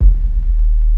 41 BASS 01-L.wav